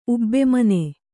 ♪ ubbmane